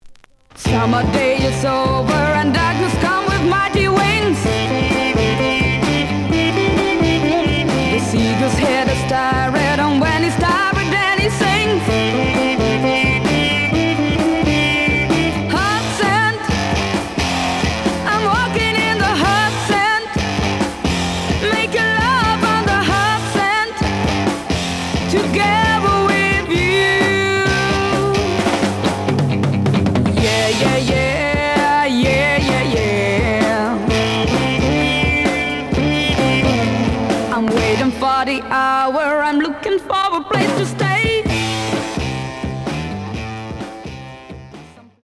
The audio sample is recorded from the actual item.
●Genre: Rock / Pop